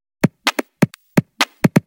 Index of /VEE/VEE Electro Loops 128 BPM
VEE Electro Loop 450.wav